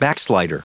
Prononciation du mot backslider en anglais (fichier audio)
Prononciation du mot : backslider